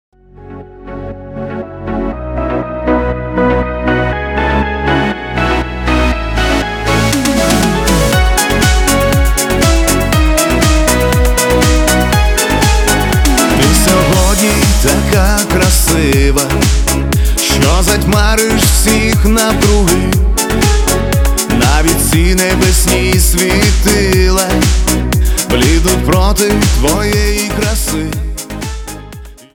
Українська музика на дзвінок 2026